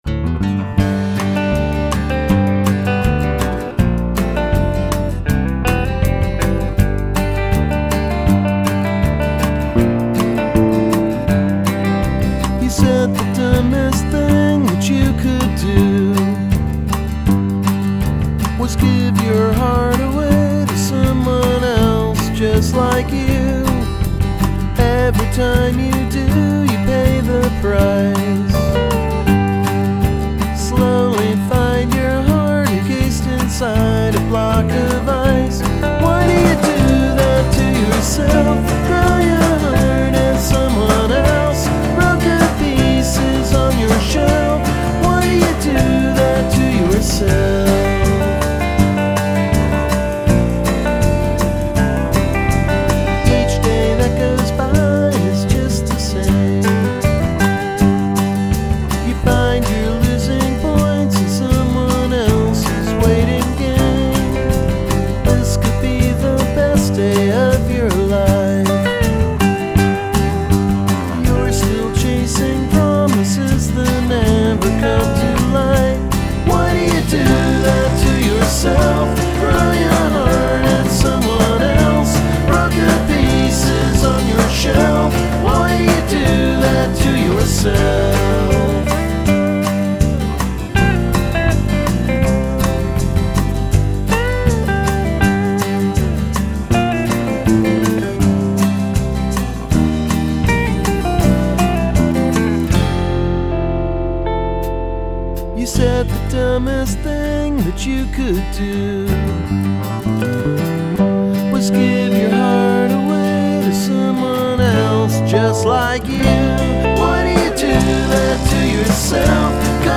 drums
a country rock affair.
of hot pickin’ and plenty of tear-inspiring tales of woe.